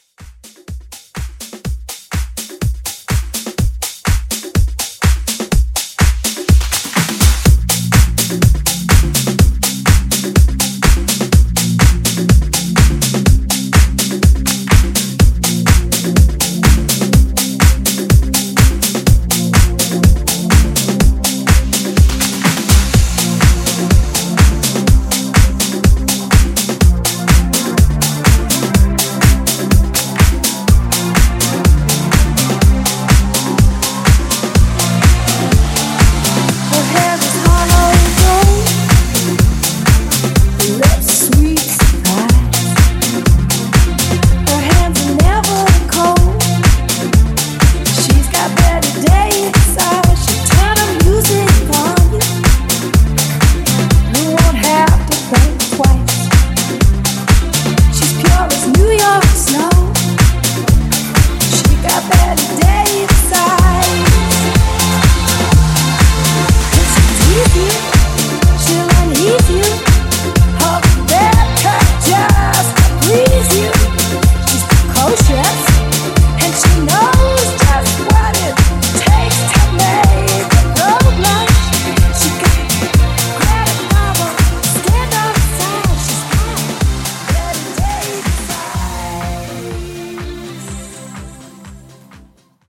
Genres: COUNTRY , TOP40
Clean BPM: 142 Time